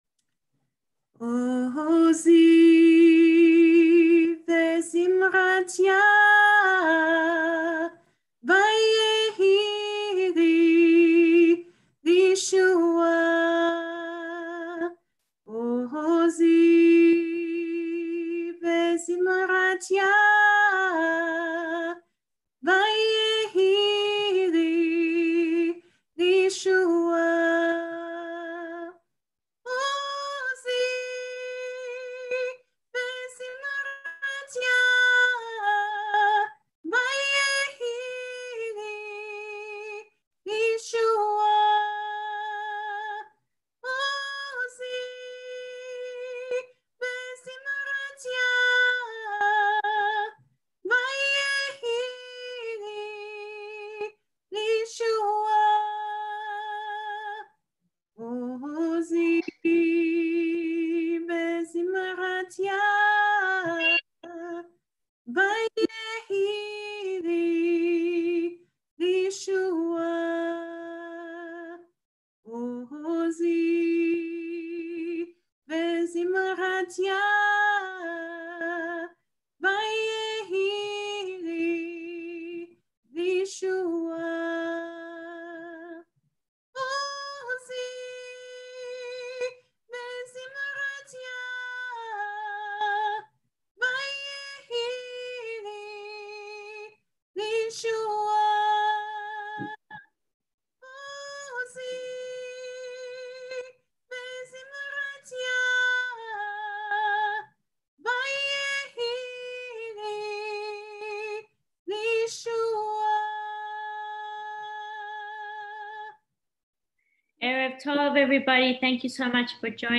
Genre Prayer